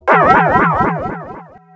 Taco Bell Swoosh 2 Sound Effect Free Download
Taco Bell Swoosh 2